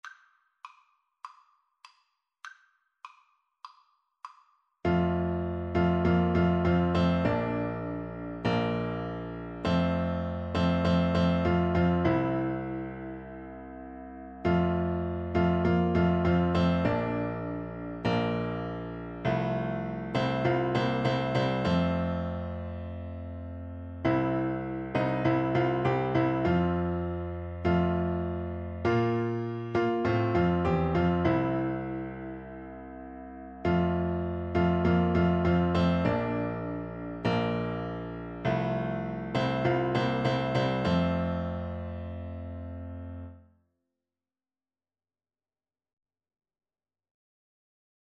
F major (Sounding Pitch) (View more F major Music for Tuba )
4/4 (View more 4/4 Music)